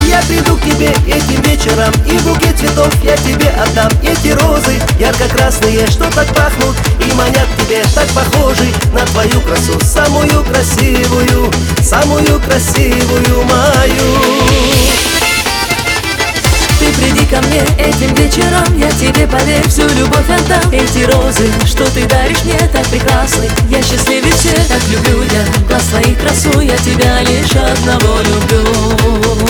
кавказские , поп
дуэт